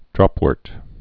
(drŏpwôrt, -wûrt)